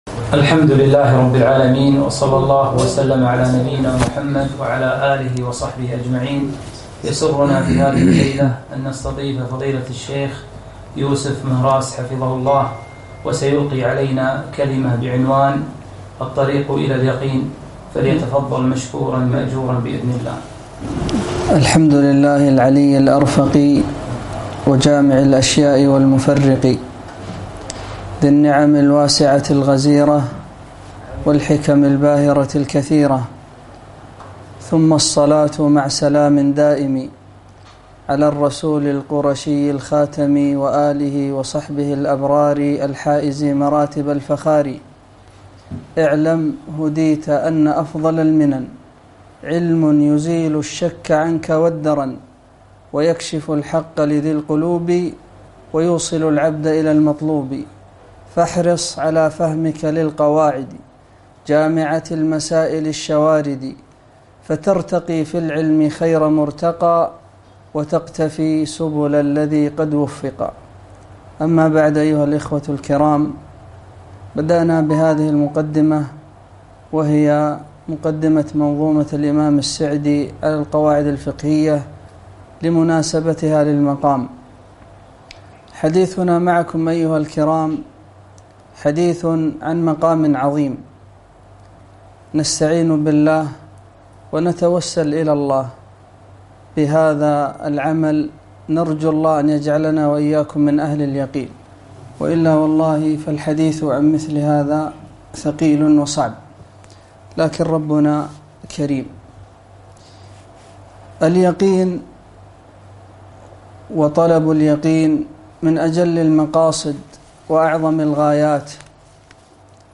محاضرة قيمة - الطريق إلى اليقين